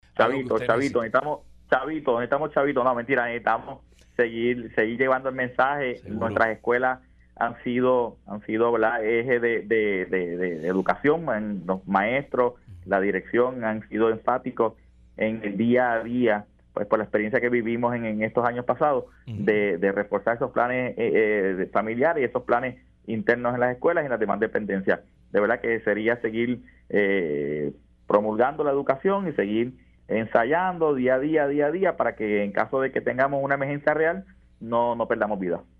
El evento fue pasadas las cinco de la mañana y ahora mismo, a la hora que estamos hablando, ya van 12 réplicas“, destacó el experto en entrevista para Dígame la Verdad.